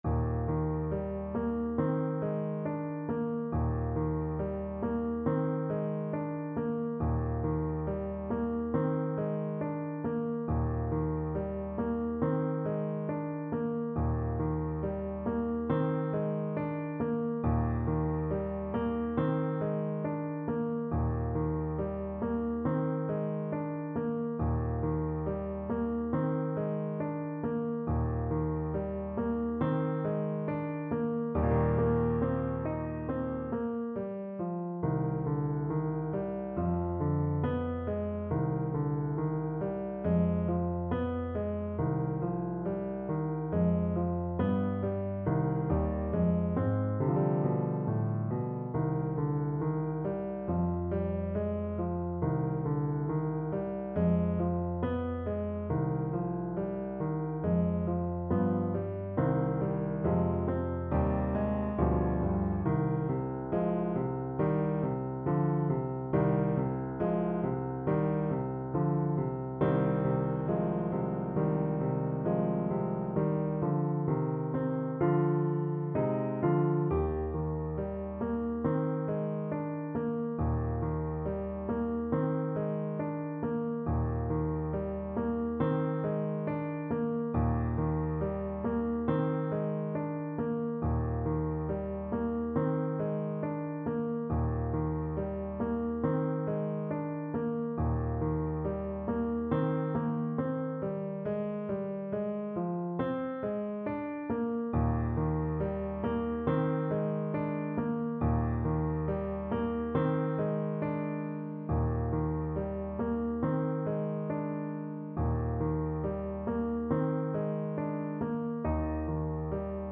Play (or use space bar on your keyboard) Pause Music Playalong - Piano Accompaniment Playalong Band Accompaniment not yet available transpose reset tempo print settings full screen
Eb major (Sounding Pitch) C major (Alto Saxophone in Eb) (View more Eb major Music for Saxophone )
~ =69 Poco andante
4/4 (View more 4/4 Music)
Classical (View more Classical Saxophone Music)